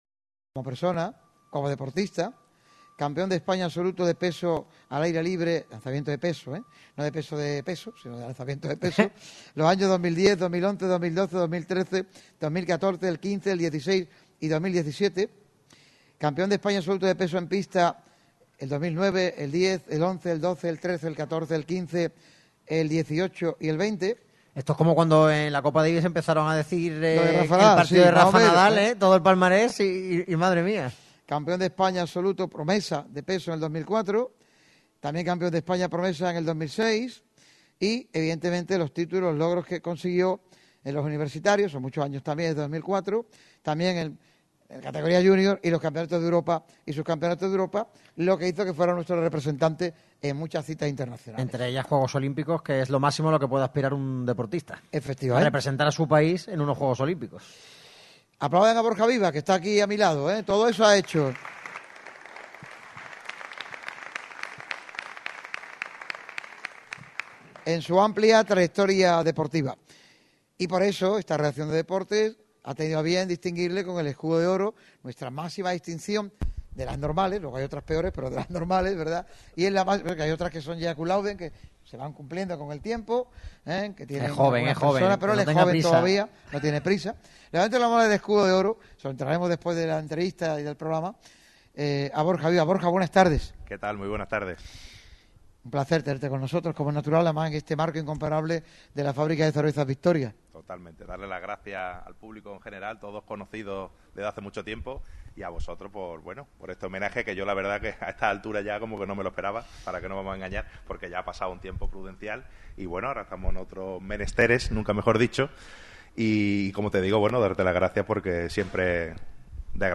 Borja Vivas, concejal de Deportes, así lo confirma en Radio MARCA Málaga. El Ayuntamiento apunta al desplazamiento del Málaga CF de La Rosaleda para la próxima temporada.